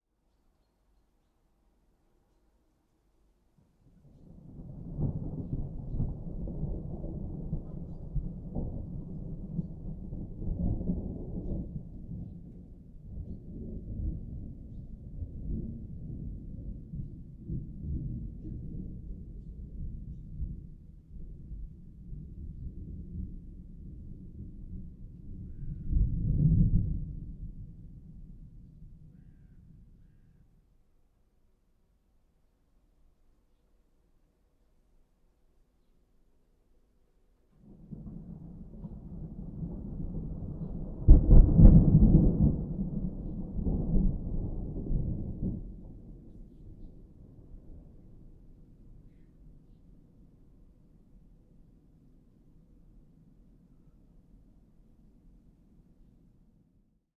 Thunderstorm_Summer_Morning_Distant_Rumble_Sparse_Approaching_2.ogg